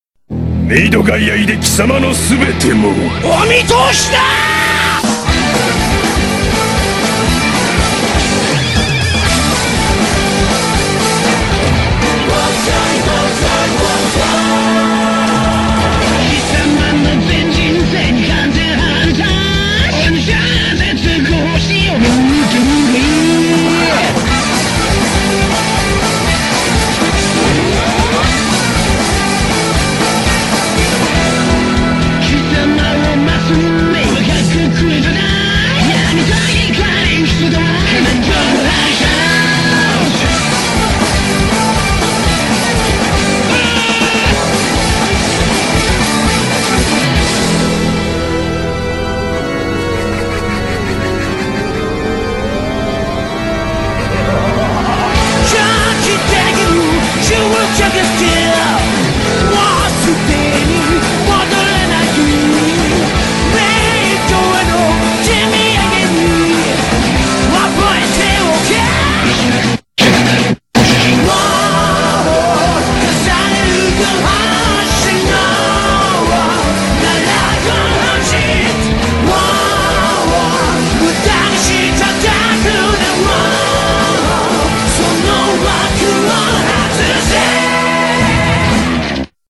BPM120-240
Audio QualityCut From Video